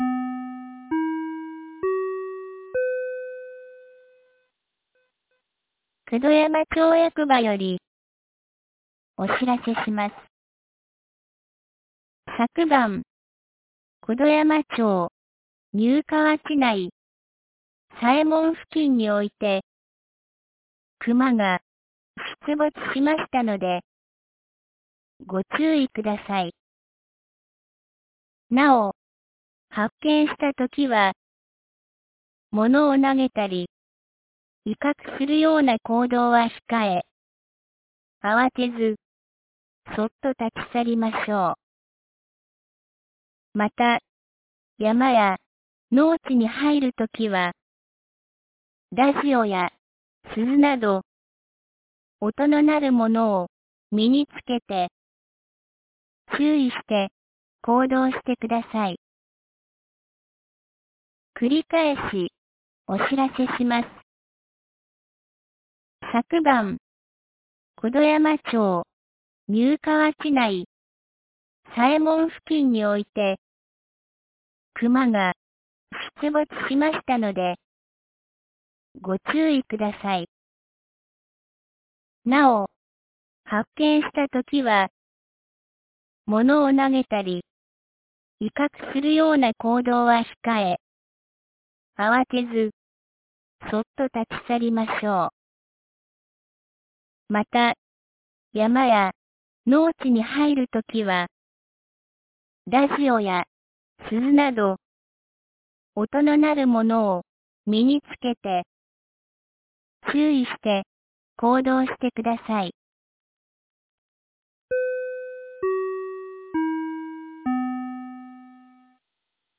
2024年11月06日 14時07分に、九度山町より河根地区、丹生川地区へ放送がありました。